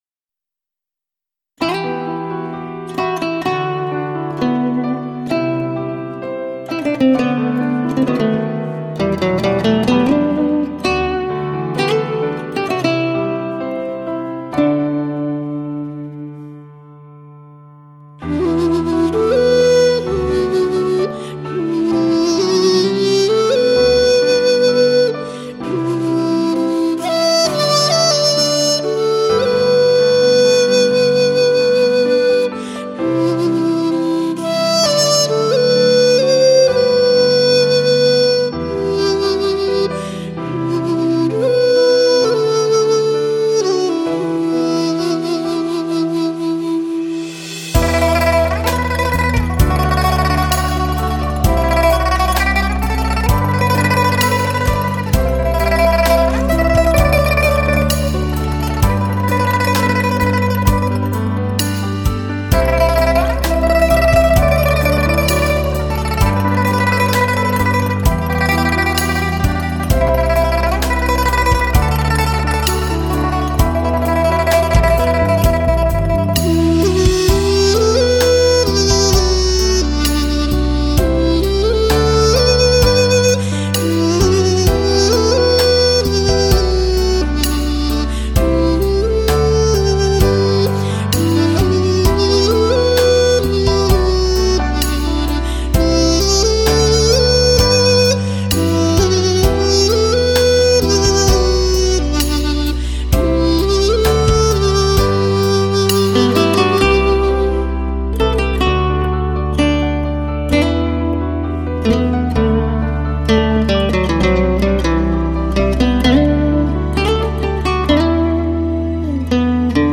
唱片以民乐 结合流行元素，表现的是多元化现代新意识音乐。